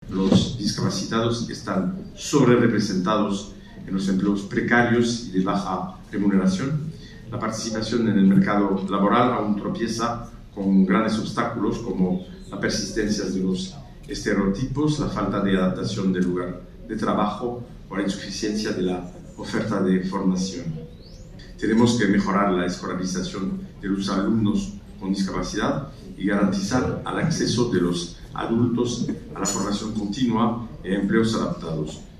Embajadores, cónsules y consejeros de embajadas participaron en la Embajada de Francia en España en el VI Encuentro Diplomacia para la Inclusión organizado por el Grupo Social ONCE y la Academia de la Diplomacia, bajo el patrocinio del embajador francés en nuestro país, que ostenta la presidencia semestral del Consejo de la UE.
En este sentido el embajador de Francia en España, Jean-Michel Casa, mostró su preocupación por el empleo de las personas con discapacidad en Europa.